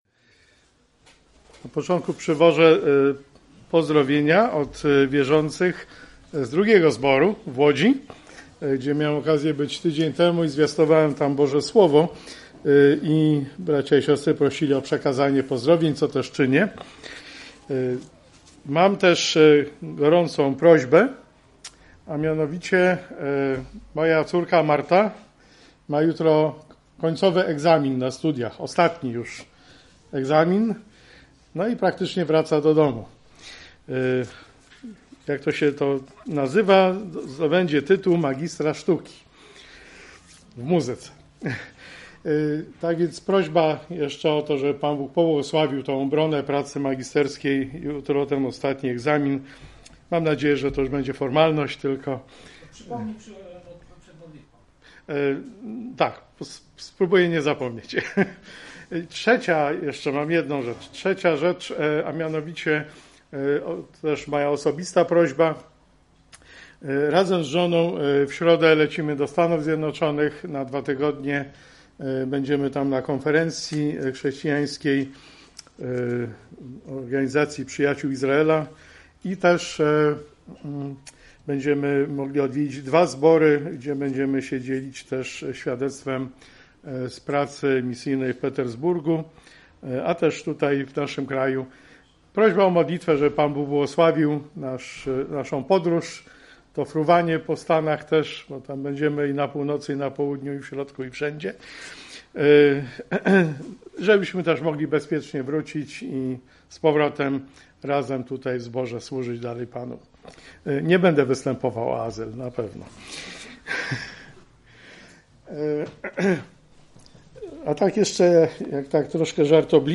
Kazania audio